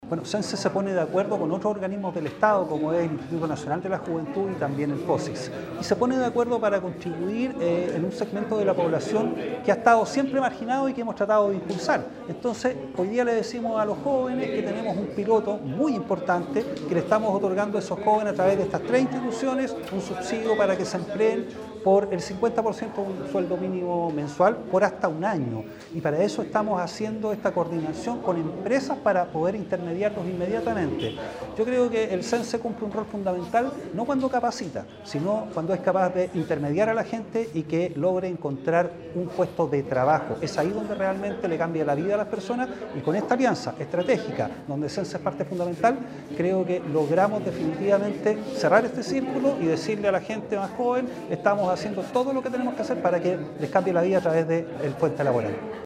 Durante la firma del convenio, el director regional del SENCE, Daniel Jana, destacó la importancia de esta alianza interinstitucional y enfatizó que “a través de estas tres instituciones, les otorgamos un subsidio del 50% de un sueldo mínimo mensual por hasta un año”.